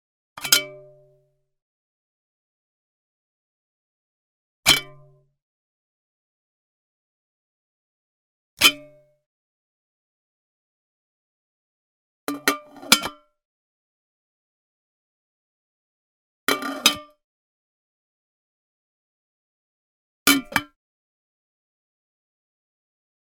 household
Metal Cooking Pot Open Lid